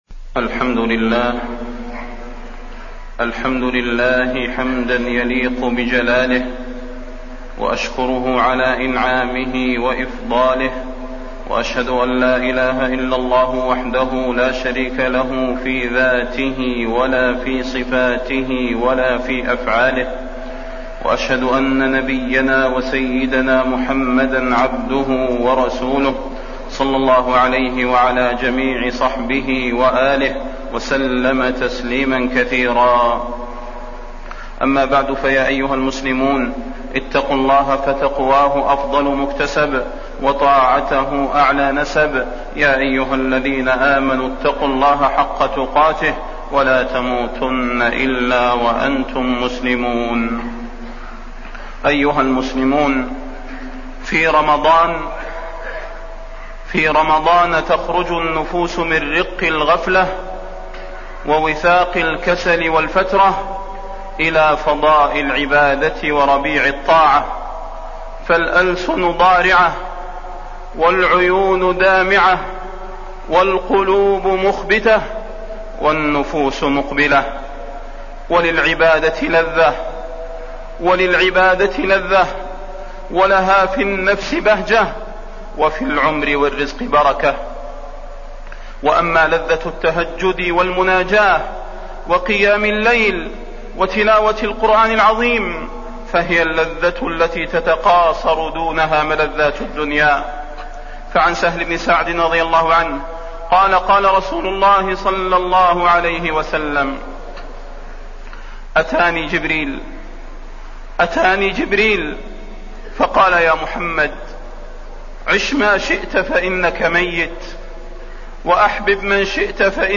فضيلة الشيخ د. صلاح بن محمد البدير
تاريخ النشر ١٩ رمضان ١٤٢٩ هـ المكان: المسجد النبوي الشيخ: فضيلة الشيخ د. صلاح بن محمد البدير فضيلة الشيخ د. صلاح بن محمد البدير العشر الأواخر وليلة القدر